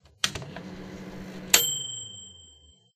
Polaris/sound/machines/ding.ogg at 948d43afecadc272b215ec2e8c46f30a901b5c18
ding.ogg